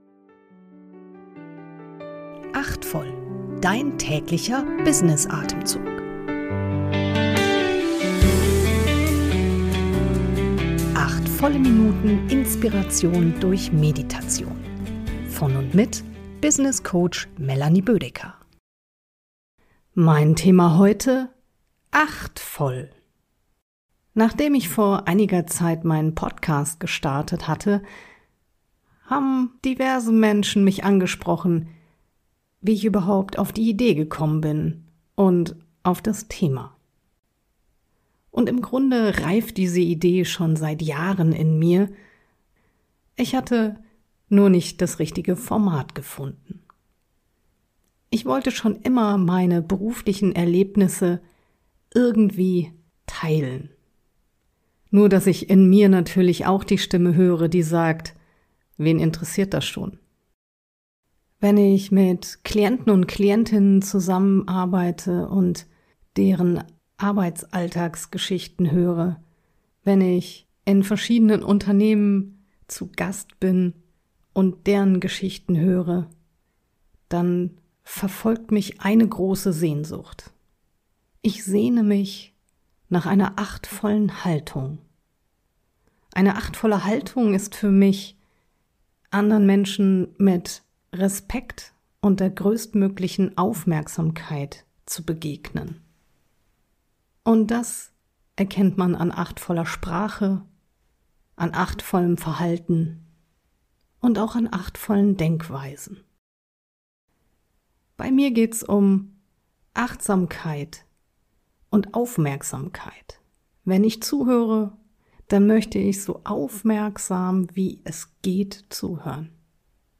durch eine geleitete Kurz-Meditation.